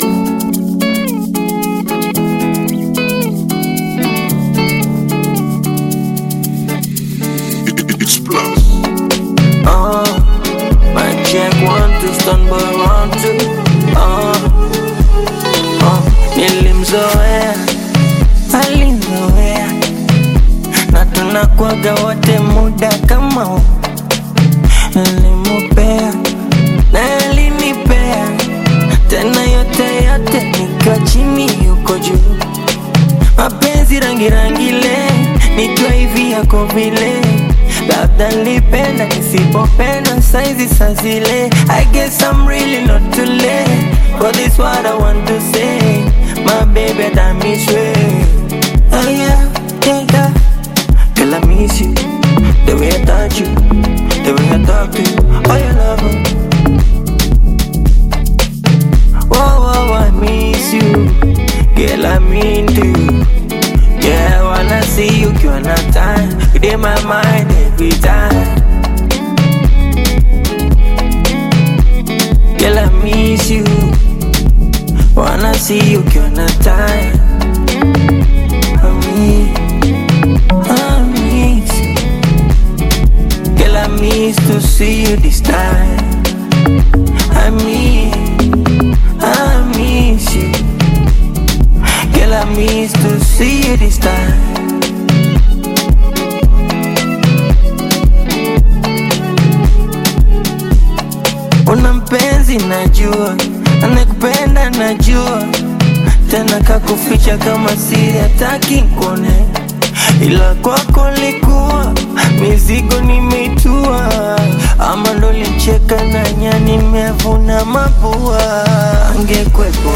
Bongo Flava
Tanzanian Bongo Flava